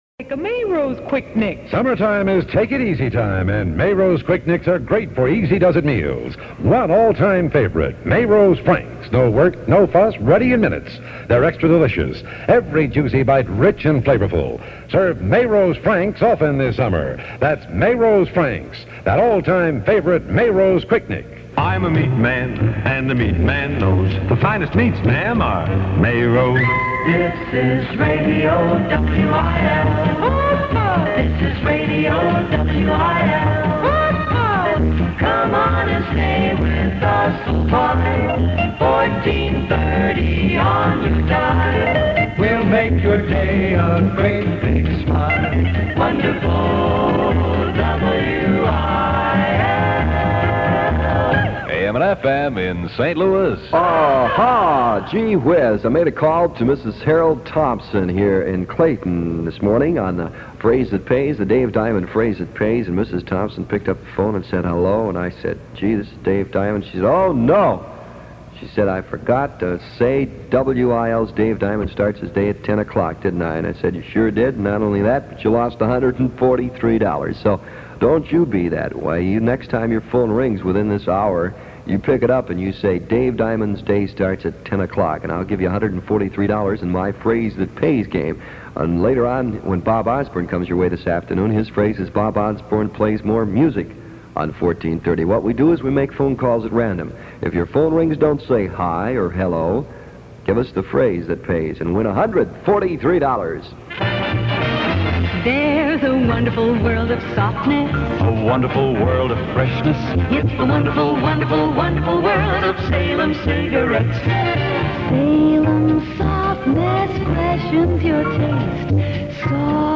Aircheck of the Week